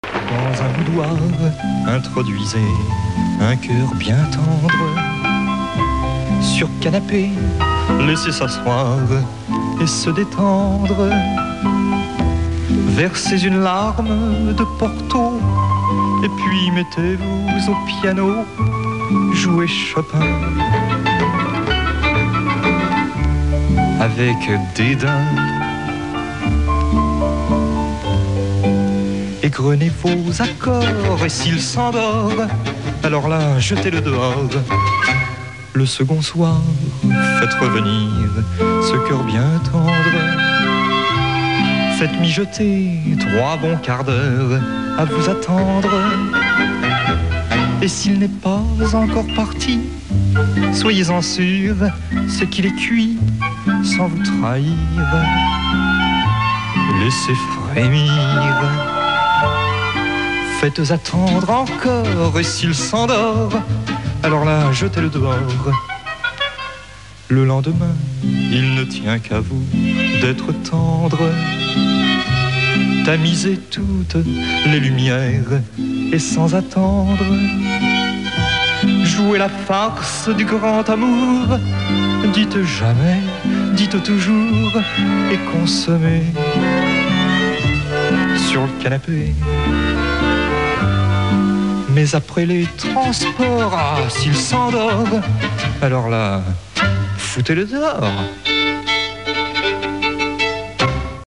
son air léger et empreint d’humour